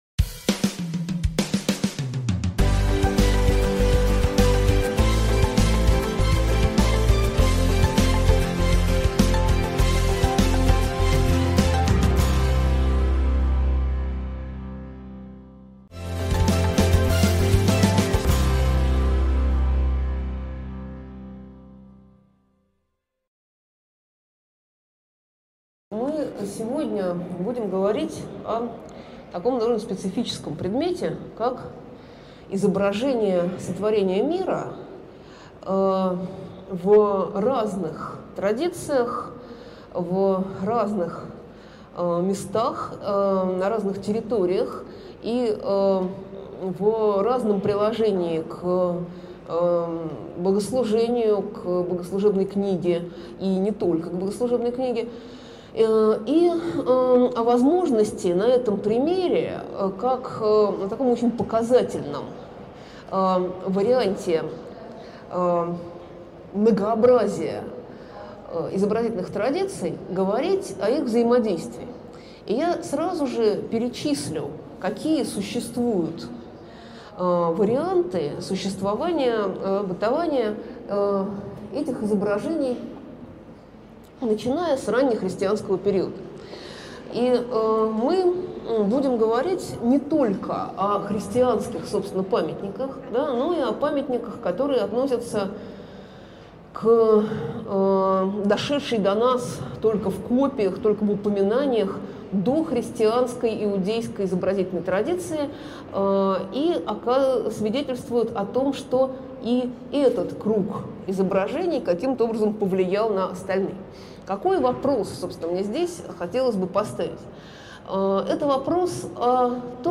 Аудиокнига Изображение Дней Творения в Западном Средневековье | Библиотека аудиокниг